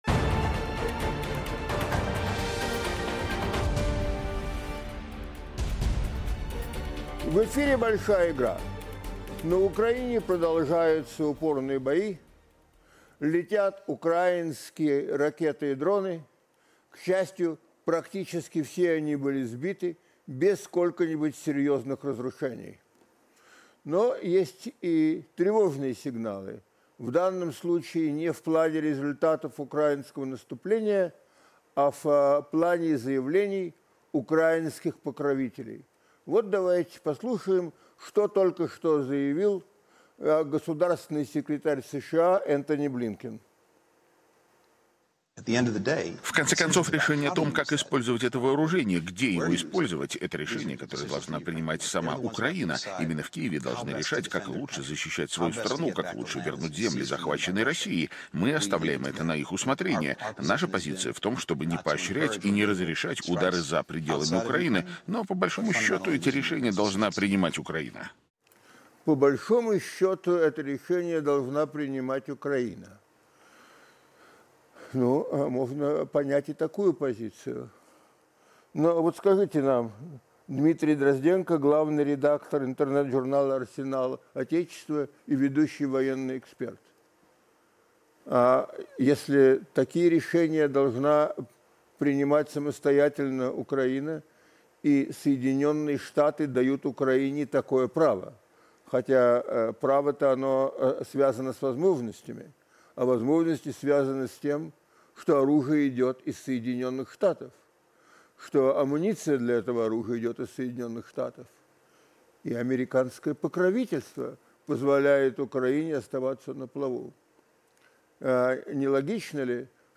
Ведущий программы Дмитрий Саймс